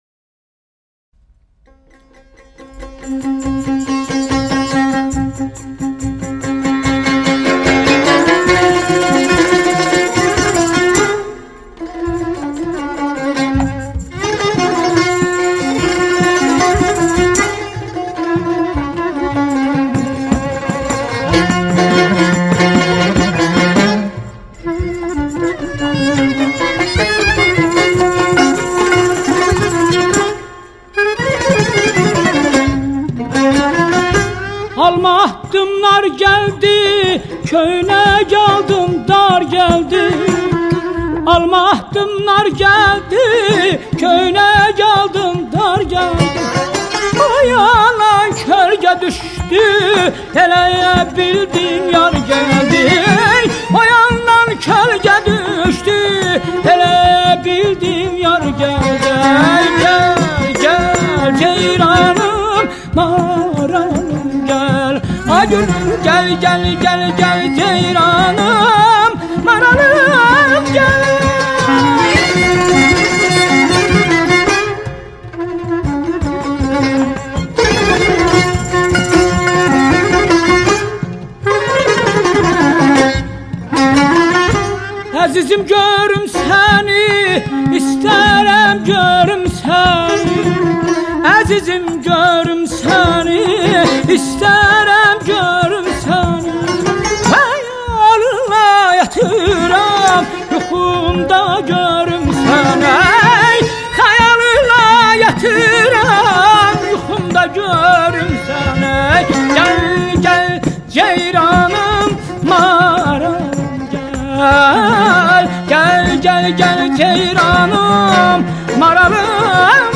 Music from Azerbaijan (Central Asia), (Azerbaijani)